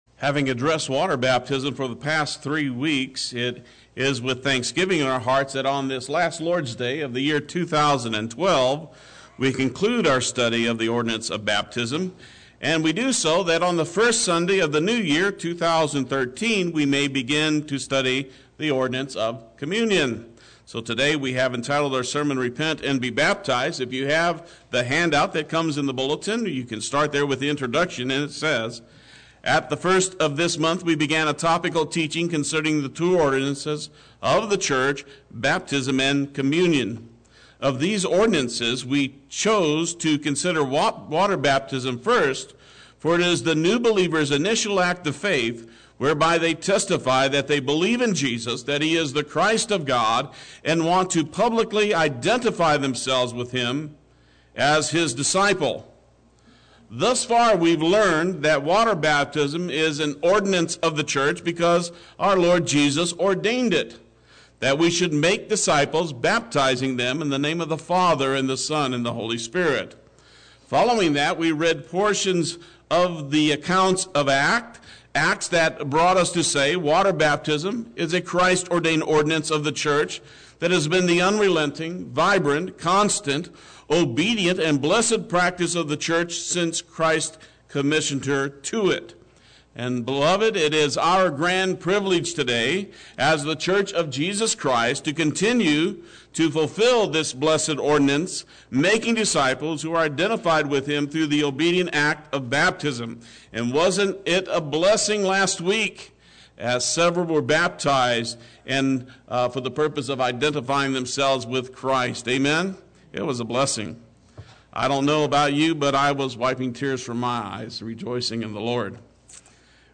Play Sermon Get HCF Teaching Automatically.
Repent and Be Baptized Sunday Worship